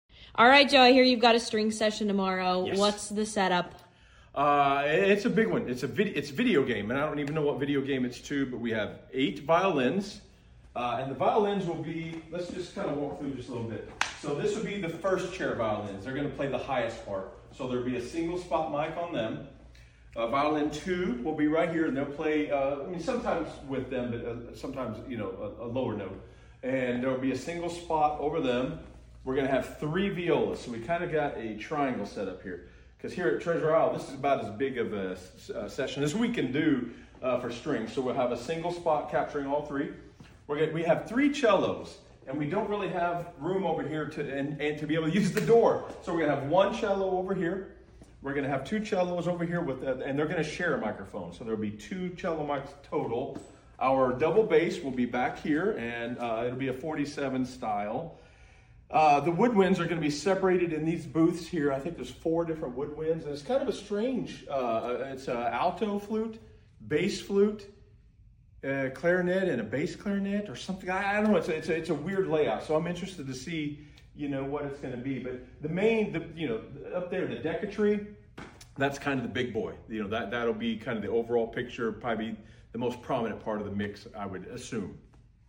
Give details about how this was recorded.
Orchestral recordings for music, movies, sound effects free download Here’s a look at a recent video game session.